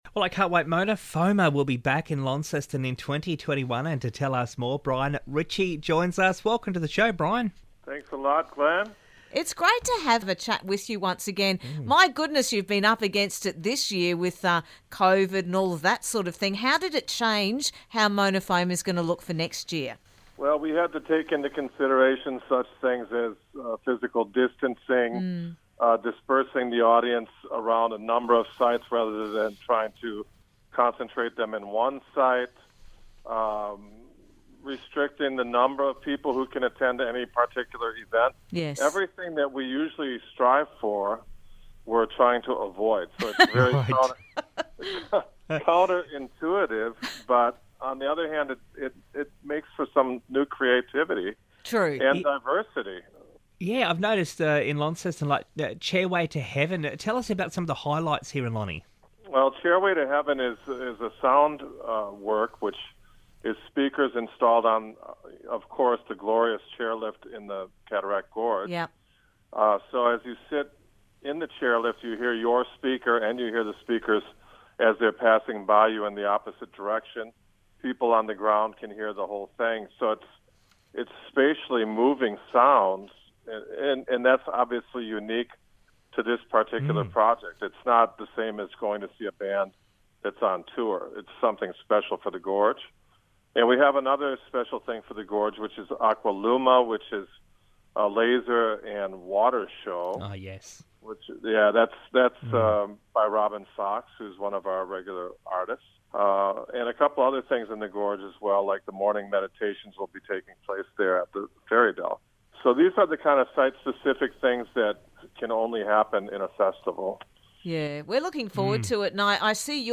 Mona Foma is back in 2021 - in Launceston 15 to 17 January and Hobart 22 to 24 January. Mona Foma curator Brian Ritchie knows all the details and we had a chat to find out more...